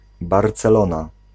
Ääntäminen
Ääntäminen Tuntematon aksentti: IPA: /ˌbɑrsəˈloːnaː/ BE: IPA: [ˌbɑrsəˈloːna] NL: IPA: [ˌbɑrsəˈloʊ̯na] Haettu sana löytyi näillä lähdekielillä: hollanti Käännös Ääninäyte 1. Barcelona {f} Suku: n .